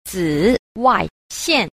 9. 紫外線 – zǐwàixiàn – tử ngoại tuyến (tia tử ngoại)